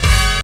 SWINGSTAB 12.wav